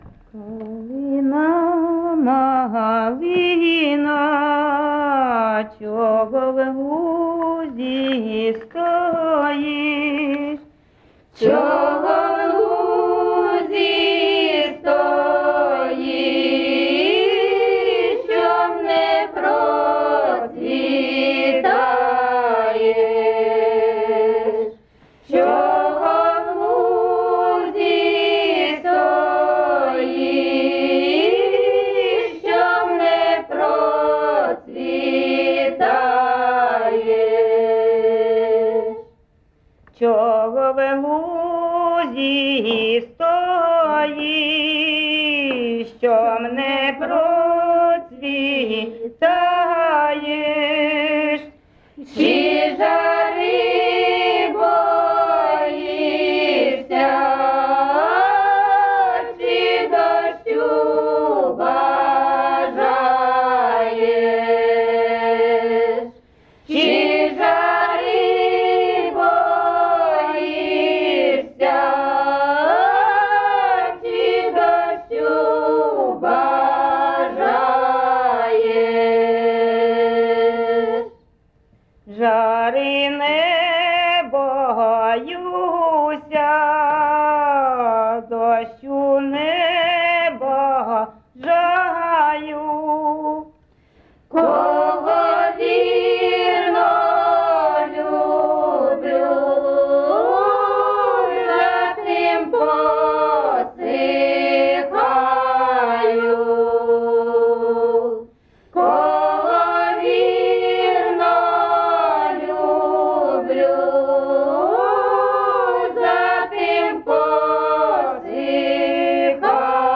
ЖанрПісні з особистого та родинного життя, Козацькі, Солдатські
Місце записус. Сковородинівка, Золочівський район, Харківська обл., Україна, Слобожанщина